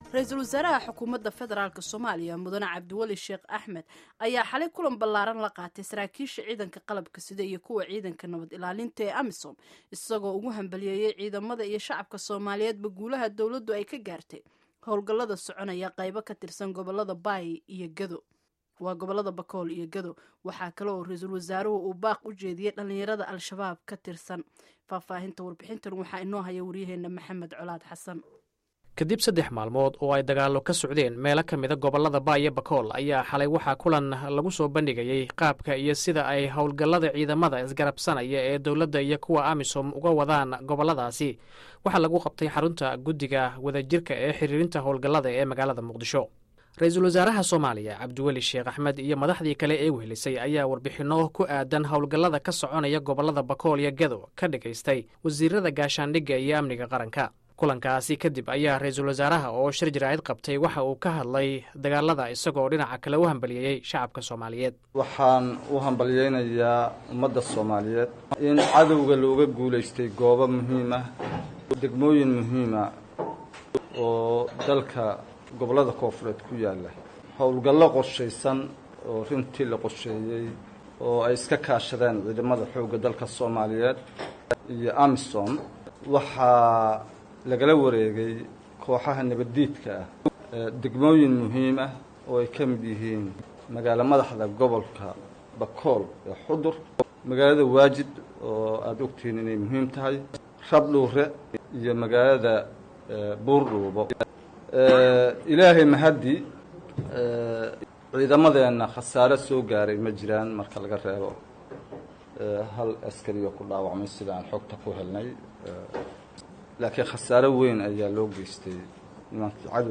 Ra’iisul wasaaraha Somalia Cabdiwali Sheekh Axmed ayaa ka hadlay dagaalada ka socda goboladii ugu dambeeyay ee ay Ciidamada dowladda Somalia iyo kuwa AMISOM ay uga soo horjeedaan ururka Al-shabab.